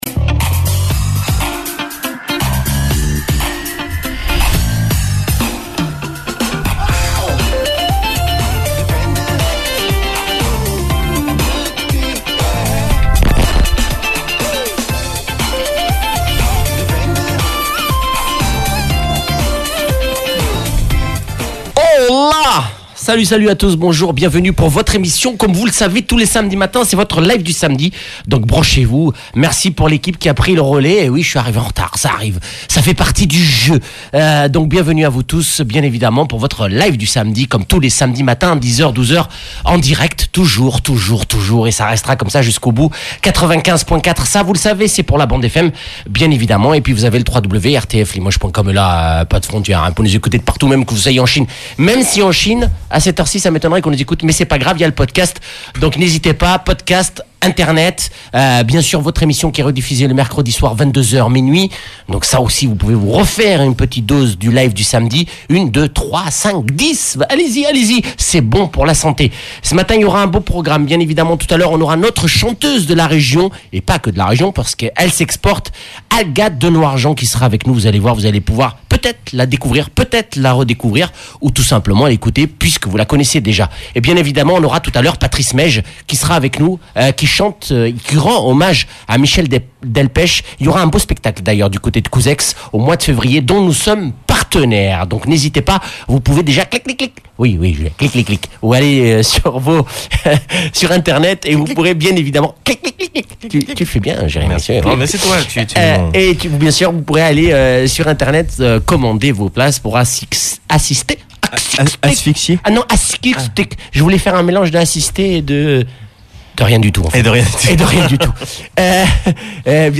avec la chanteuse